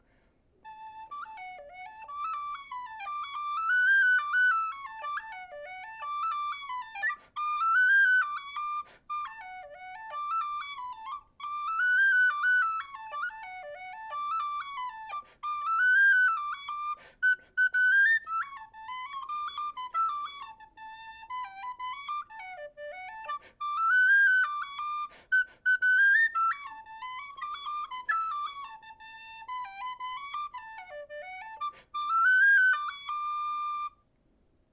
Number: #32 Key: High D Date completed: March 2025 Type: A tunable brass model with black plastic fipple plug. Volume: Very quiet - the upper end of the second octave gets a bit louder.